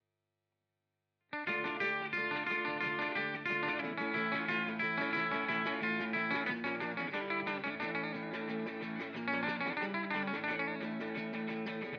Αναλογική αισθητική στην ψηφιακή εποχή: Συγκριτική μελέτη αναλογικών και ψηφιακών τεχνικών στην ηχογράφηση και μίξη της ηλεκτρικής κιθάρας